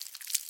sounds / mob / silverfish / step3.mp3